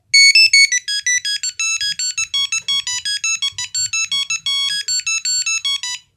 Звонок старого мобильника